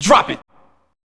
AMB147VOX-L.wav